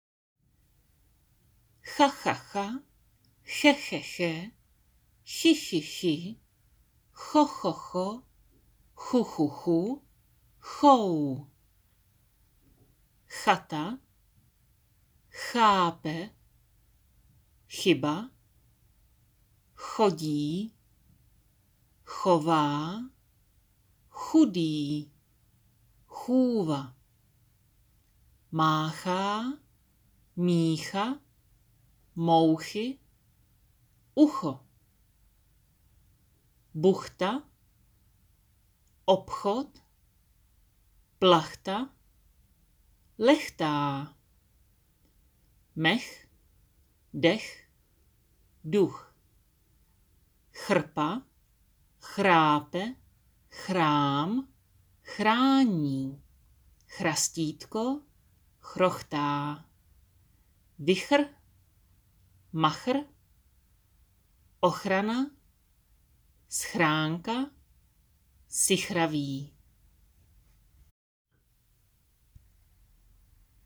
Audio na výslovnost CH - slova